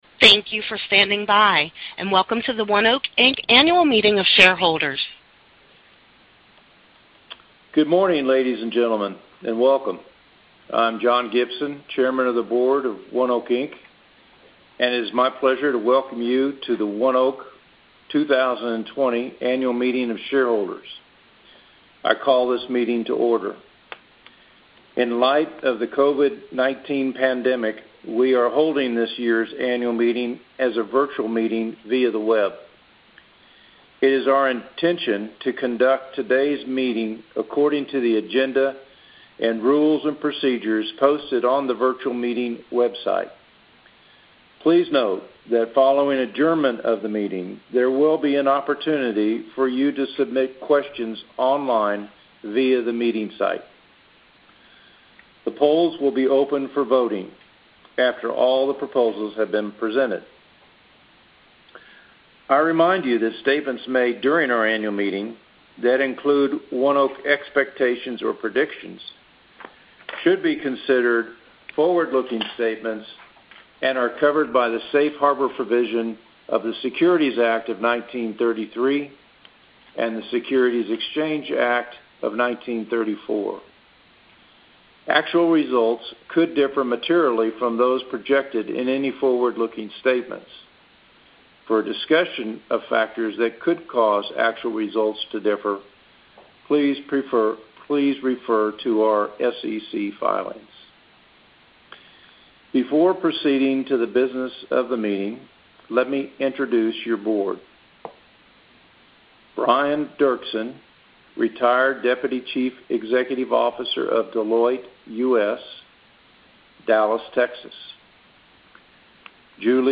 oneok-agm-audio-recording.mp3